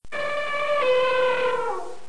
c_elep_atk1.wav